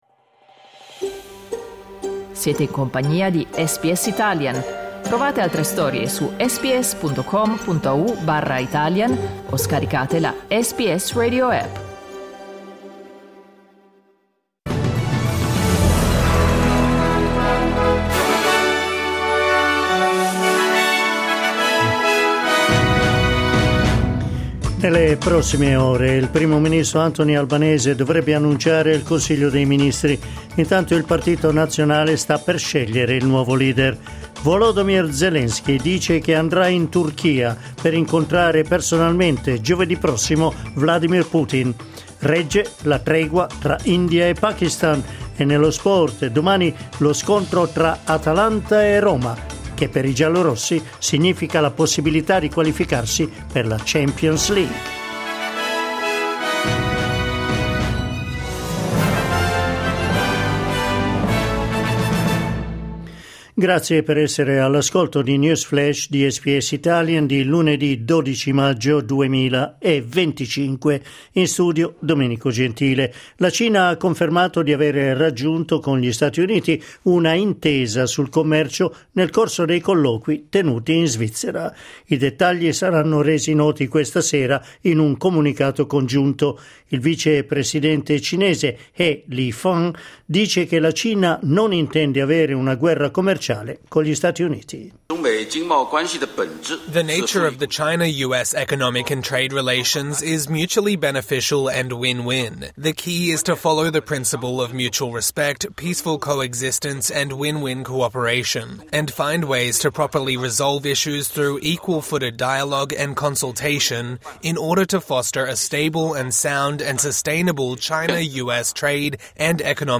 News flash lunedì 12 maggio 2025
L’aggiornamento delle notizie di SBS Italian.